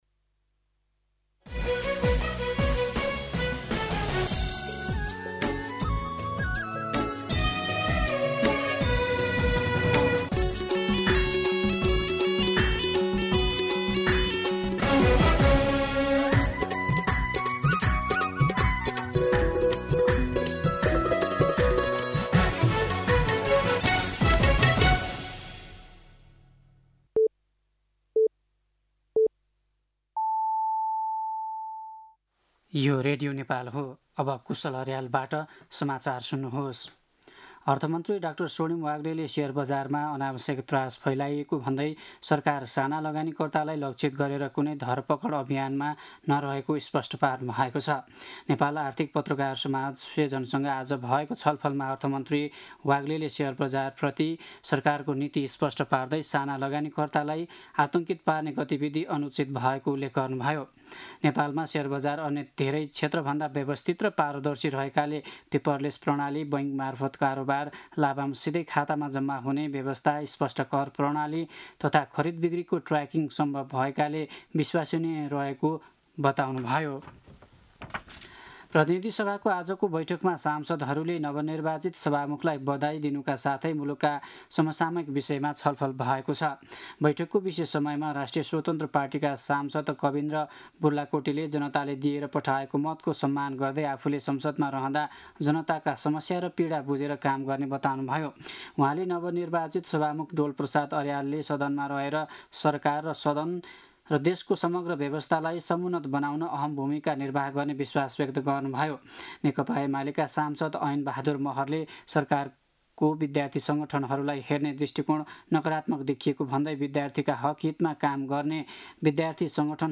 दिउँसो ४ बजेको नेपाली समाचार : २३ चैत , २०८२
4-pm-News-23.mp3